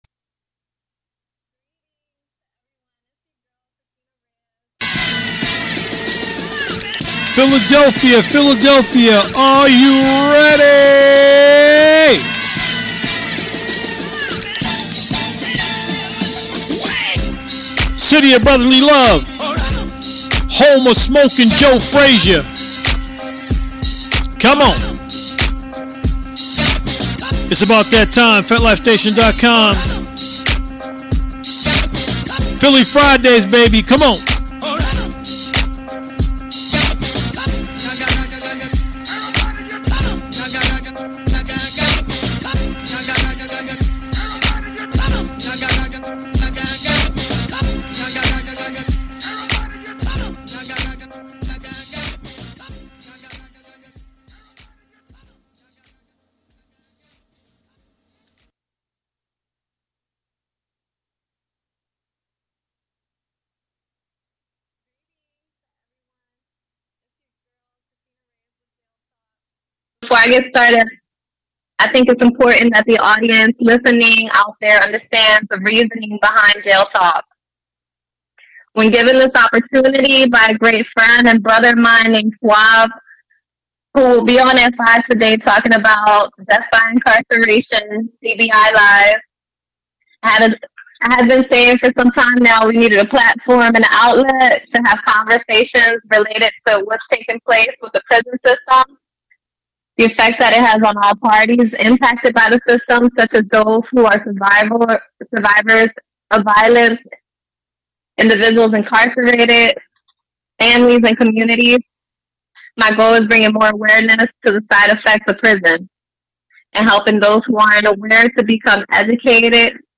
interview people who have builded love relationships with people in prison.